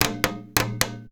PERC 13.AI.wav